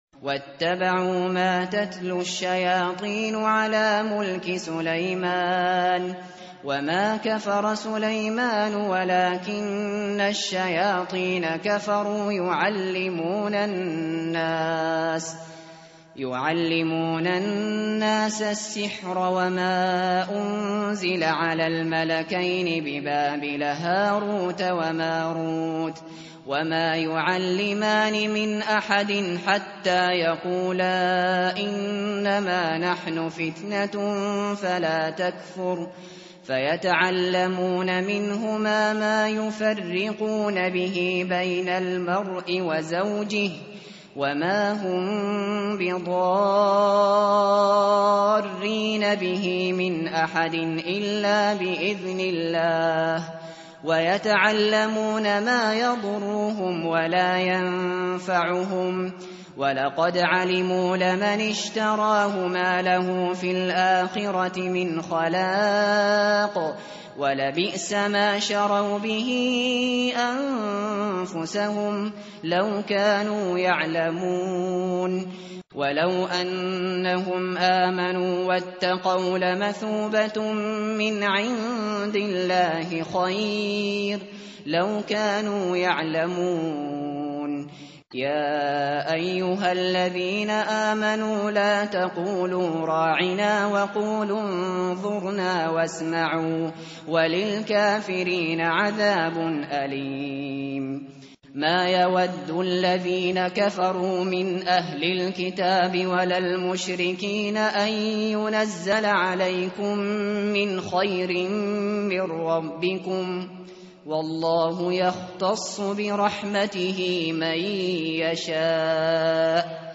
tartil_shateri_page_016.mp3